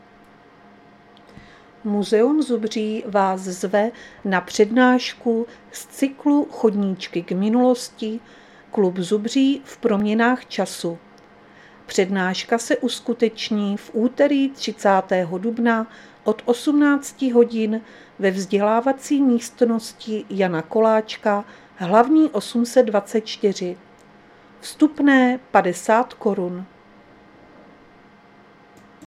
Záznam hlášení místního rozhlasu 30.4.2024
Zařazení: Rozhlas